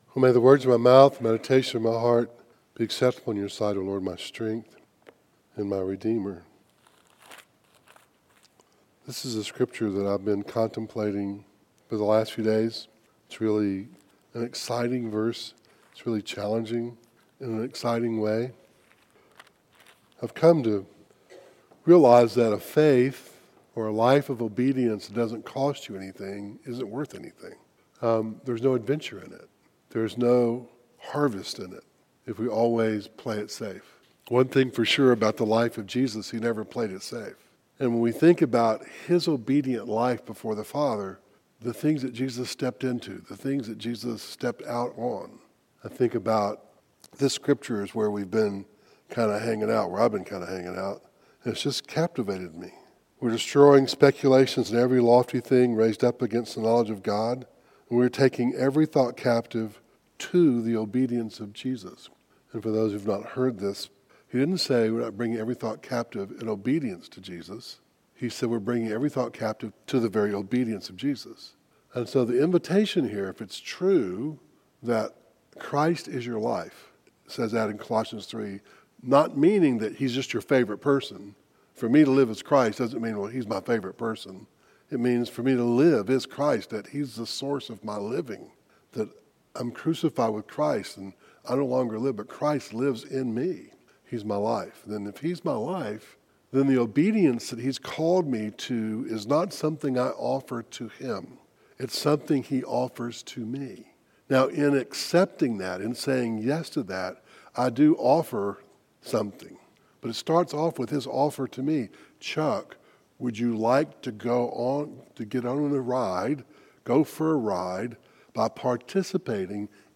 Audio Devotionals